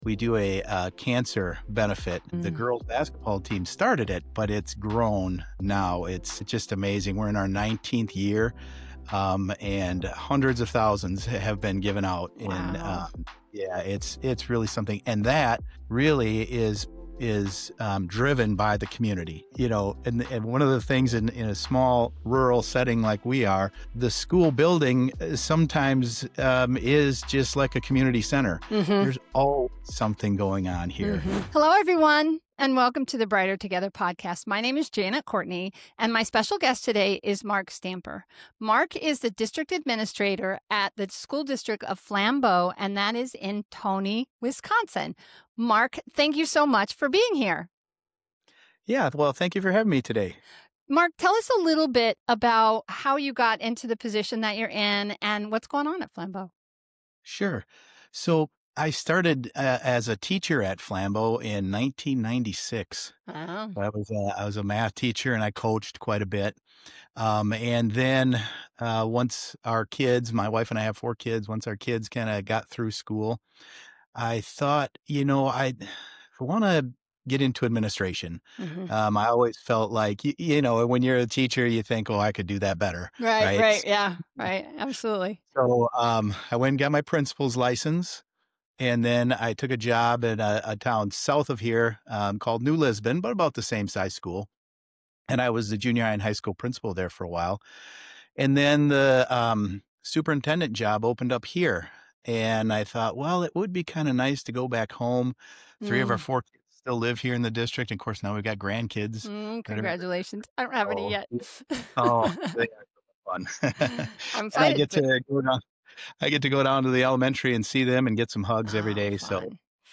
Don’t miss this game-changing conversation.